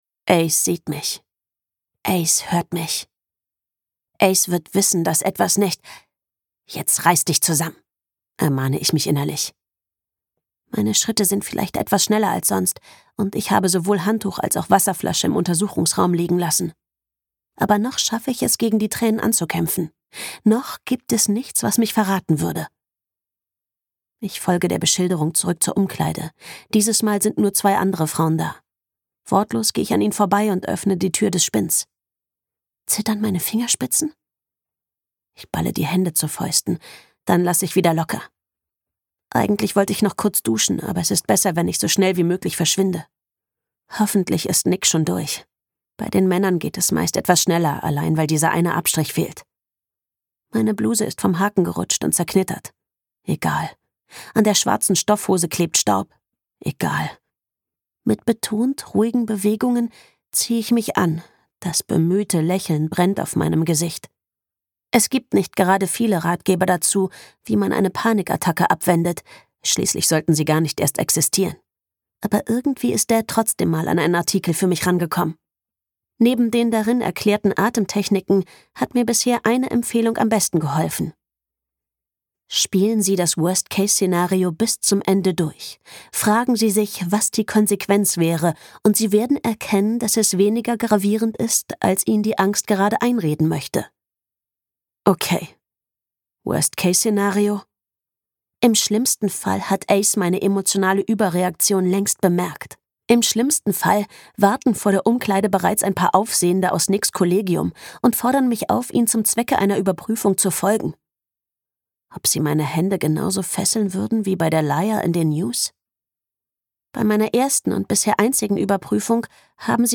2024 Argon Hörbuch